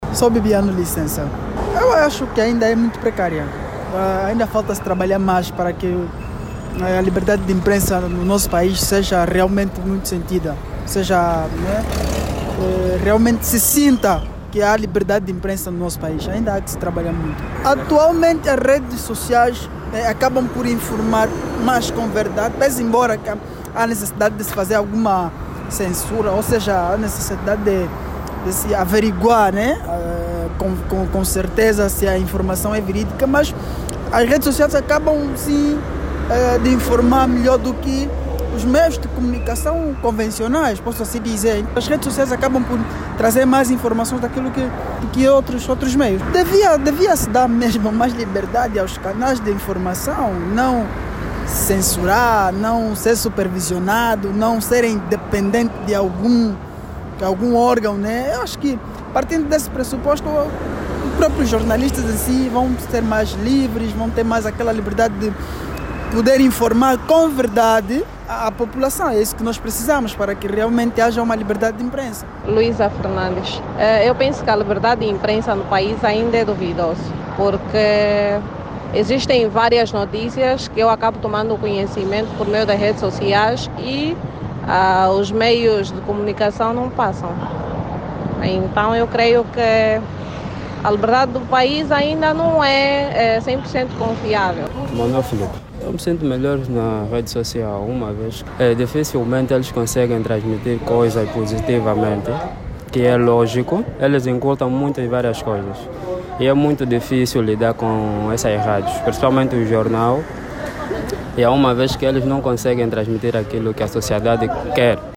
E por cá, os cidadãos entrevistados pela RÁDIO NOVA, questionam a liberdade de imprensa em Angola e revelam que, muitas vezes, acreditam mais nas informações avançadas nas redes sociais como, por exemplo, Facebook e WhatsAp em detrimento dos meios tradicionais.